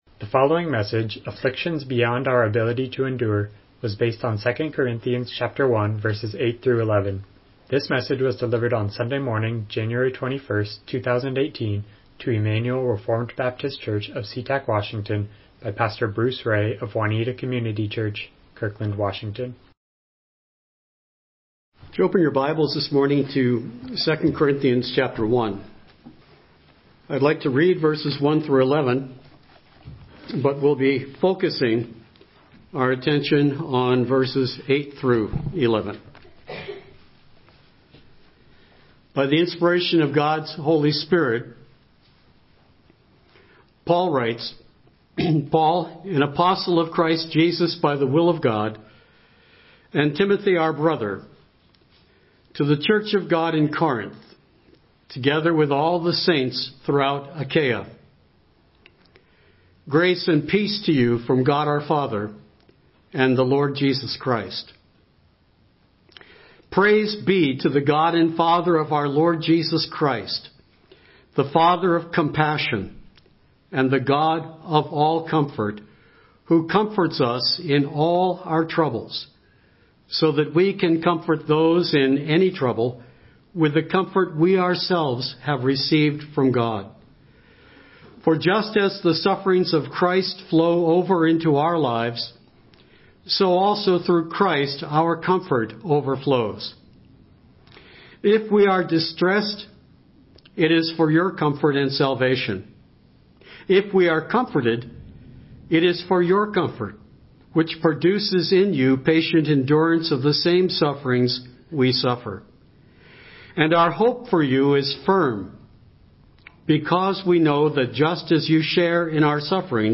Miscellaneous Passage: 2 Corinthians 1:8-11 Service Type: Morning Worship « Biblical Counseling Why Do We Run?